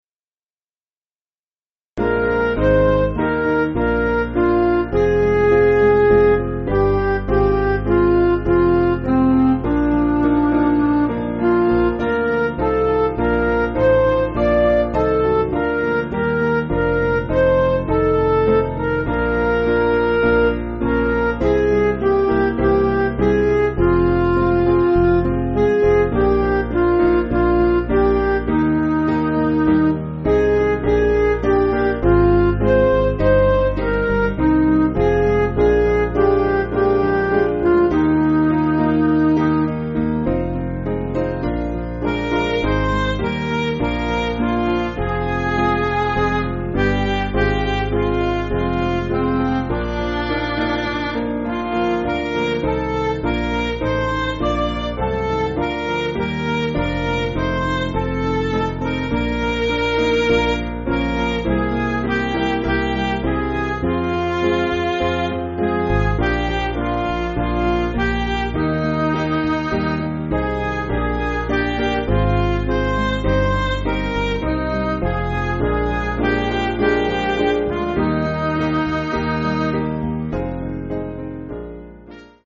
Piano & Instrumental
(CM)   3/Eb
Midi